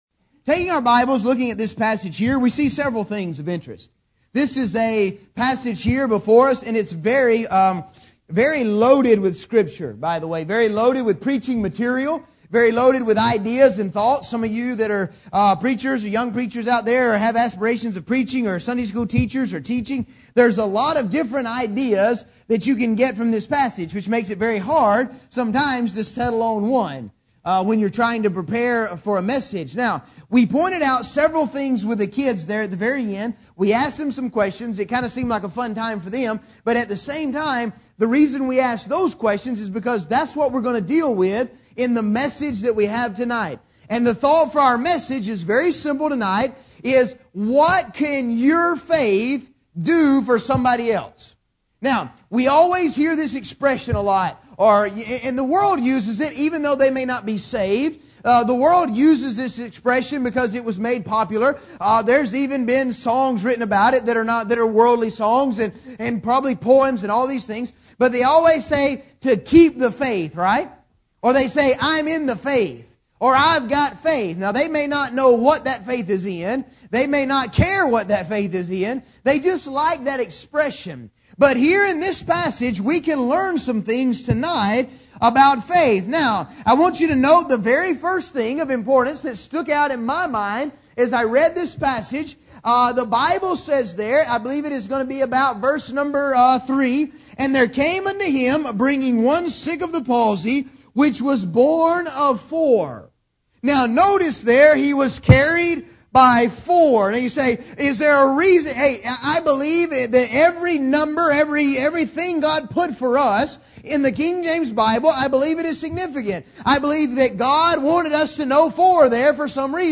This is a sermon on the four men that carried the fellow who was sick with the palsy to Jesus Christ.